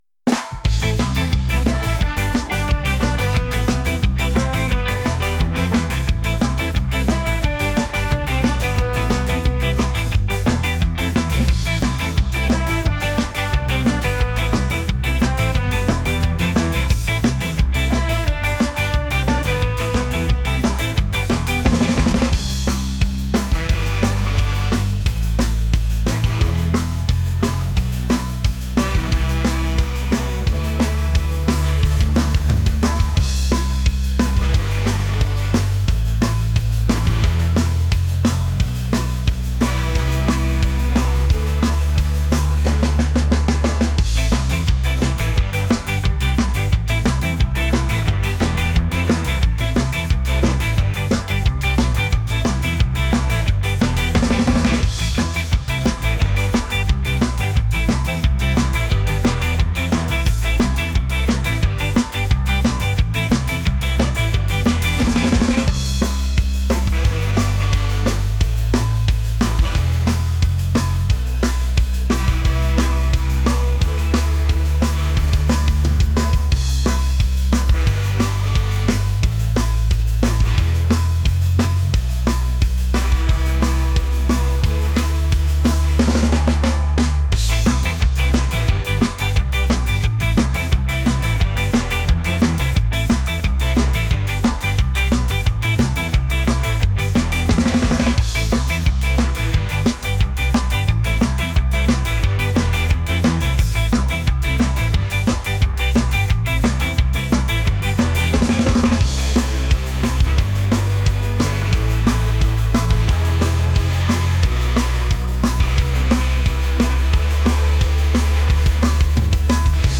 ska | reggae